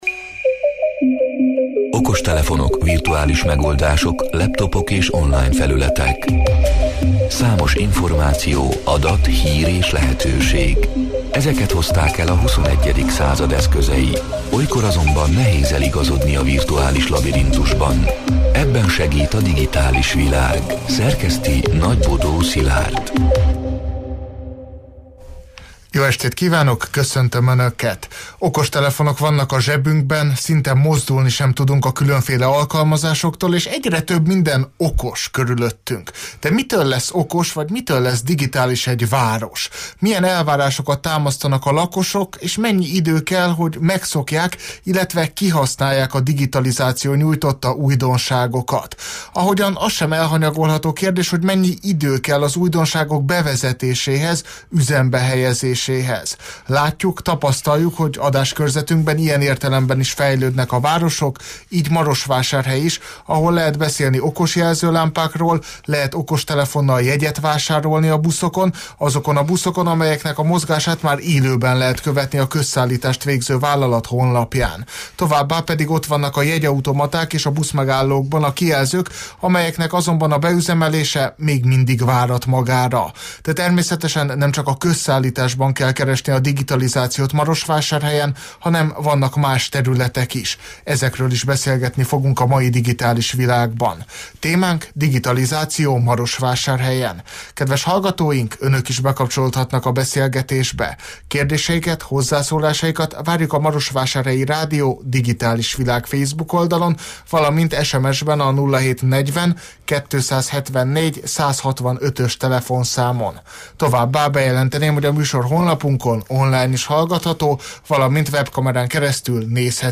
A Marosvásárhelyi Rádió Digitális Világ (elhangzott: 2026. január 13-án, kedden este nyolc órától élőben) c. műsorának hanganyaga: